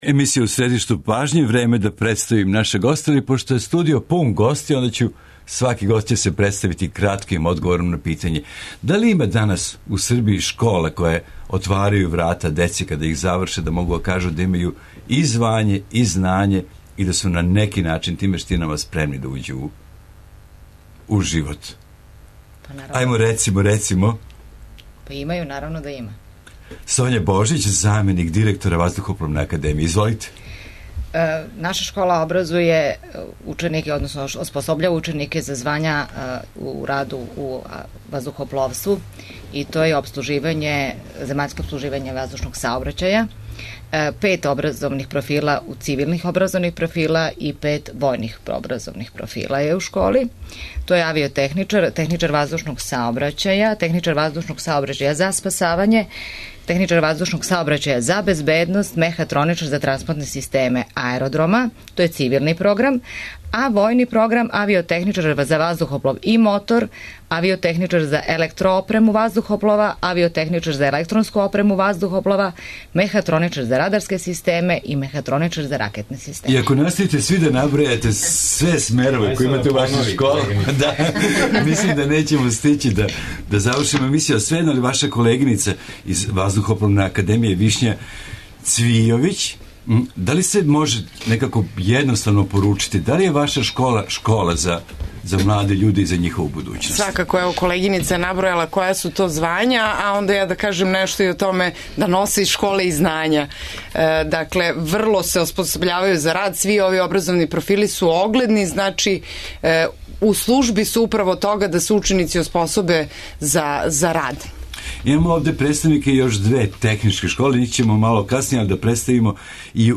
Где се школују прави мајстори и шта је све потребно да би се стекло такво звање? Представници неколико стручних средњих школа биће наши гости.